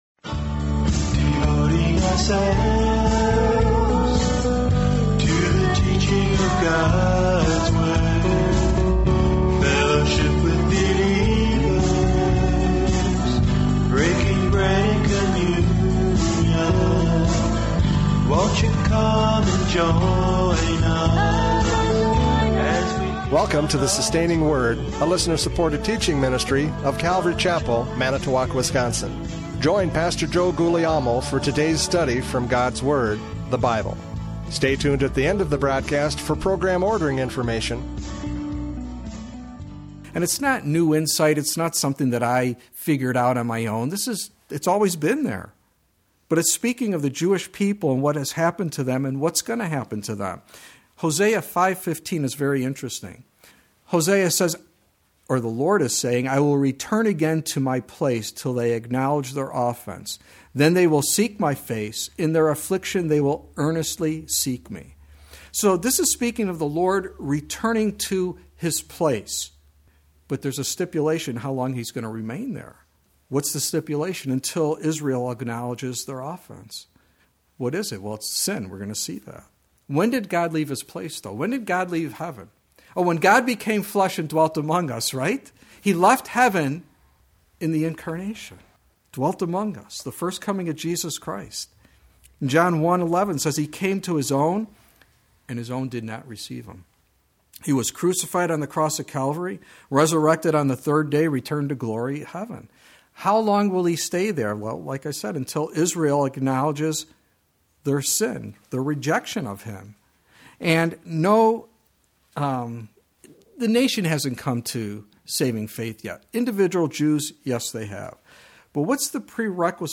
Radio Studies Service Type: Radio Programs « Prophecy Update 2023 “Oh My Gosh!”